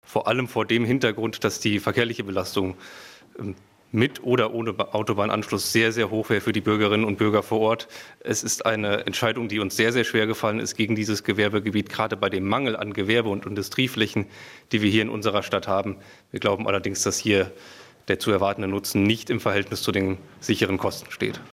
meinte am Radio Siegen-Mikrophon